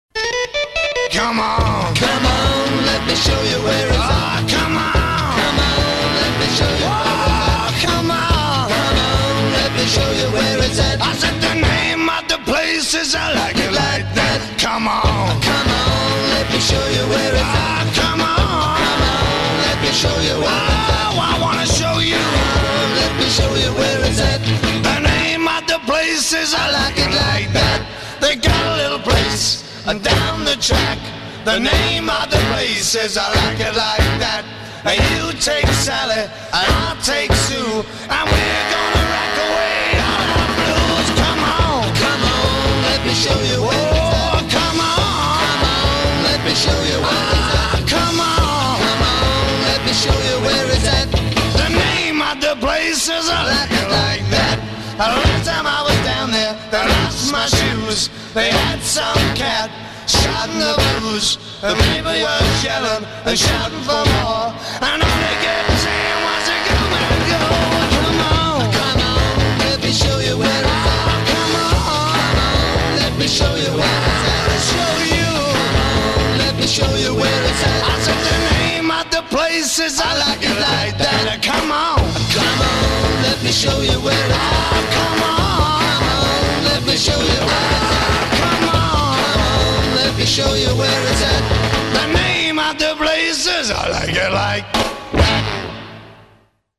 бит
поп-рок